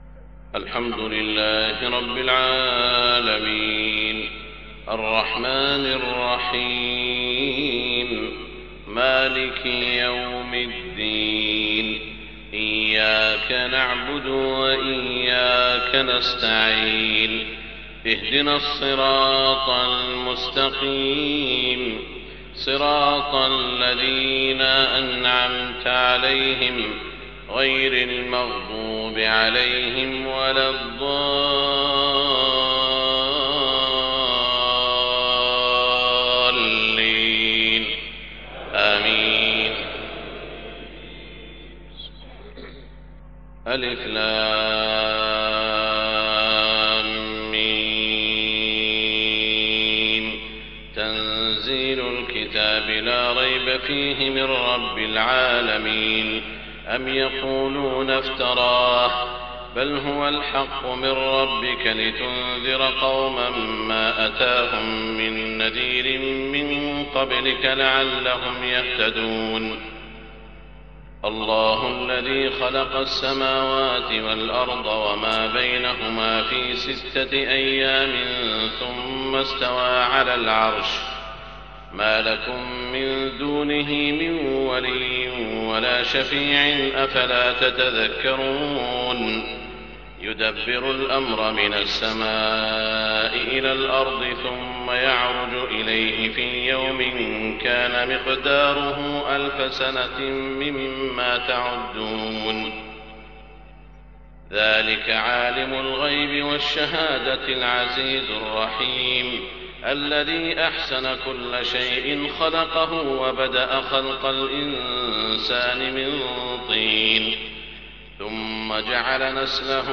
صلاة الفجر 30 ربيع الأول 1430هـ سورتي السجدة و الإنسان > 1430 🕋 > الفروض - تلاوات الحرمين